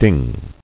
[ding]